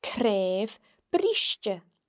Let’s have a look at how the consonants cn are pronounced in Gaelic.